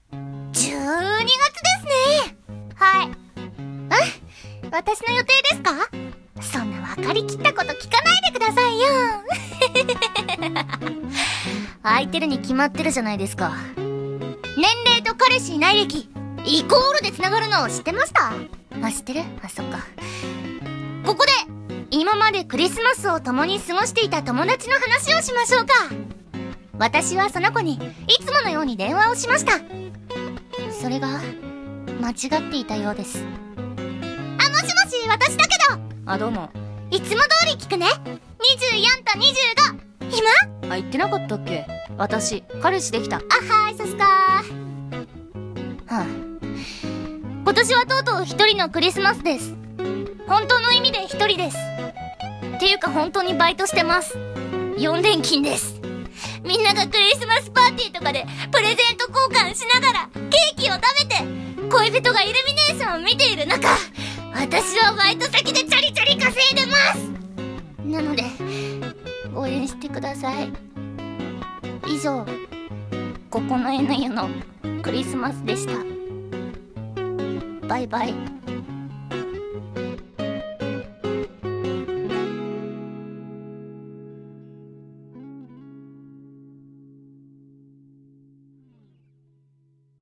【1人声劇】孤独なクリスマス